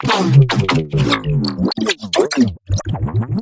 Game Foley » Squish Foley 01
描述：Foley sounds made with mouth. CAD E100S > Marantz PMD661
标签： squished juicy gamefoley squish splat
声道立体声